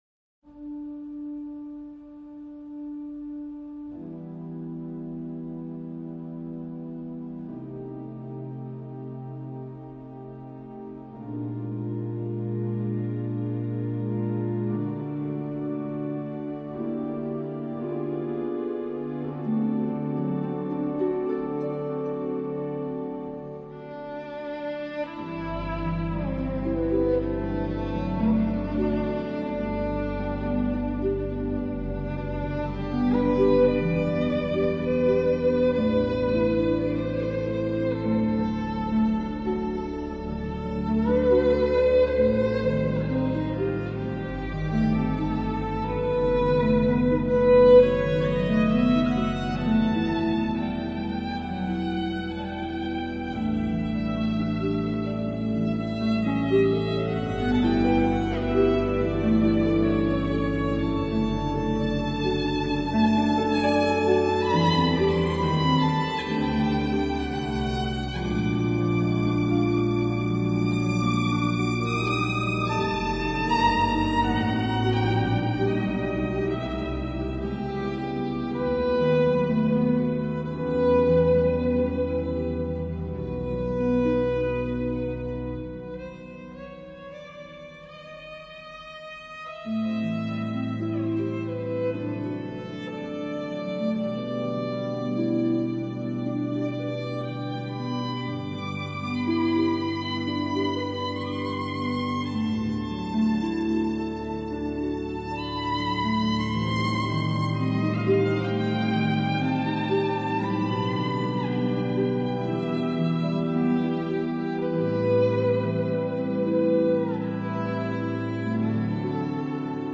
violino
arpa